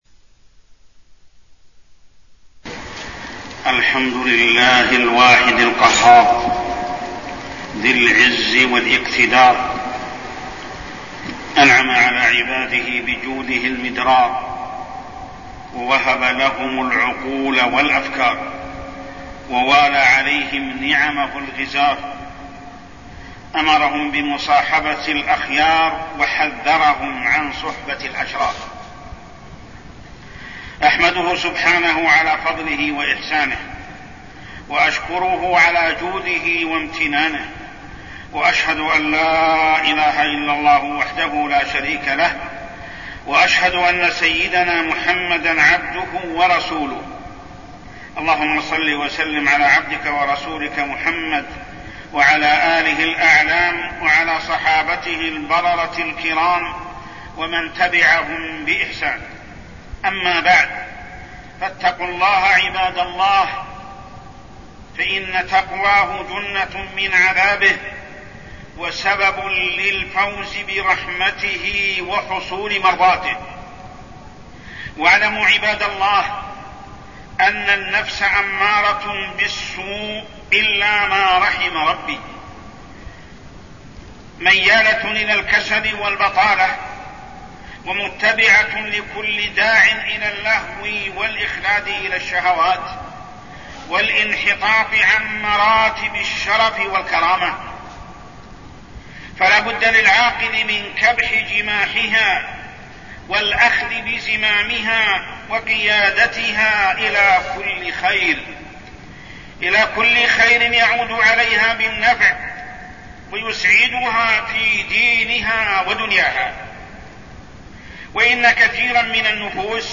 تاريخ النشر ٩ صفر ١٤١٦ هـ المكان: المسجد الحرام الشيخ: محمد بن عبد الله السبيل محمد بن عبد الله السبيل الجلساء